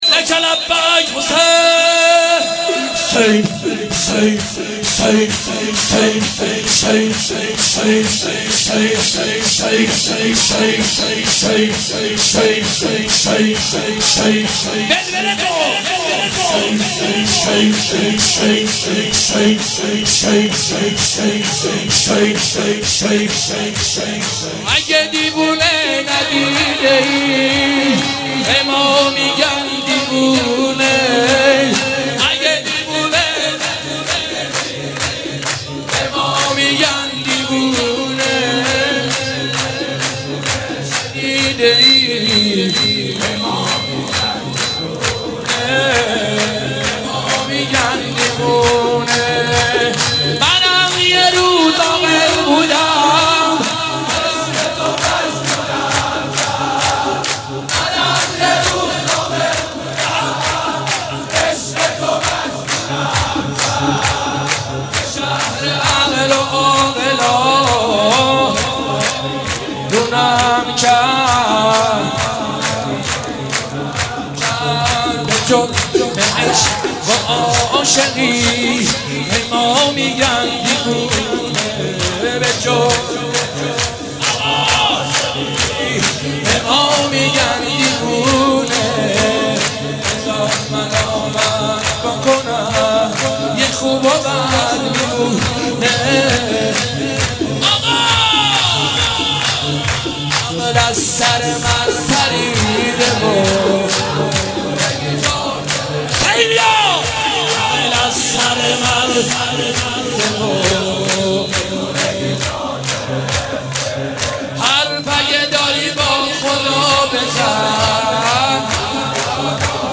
جشن نیمه شعبان/هیت روضه العباس(ع)